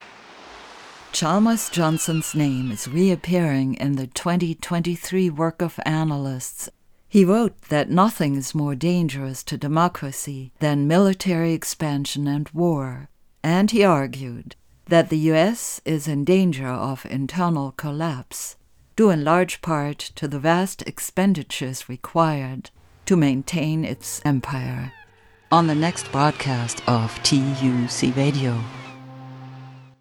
Location Recorded: MLK Junior High in Berkeley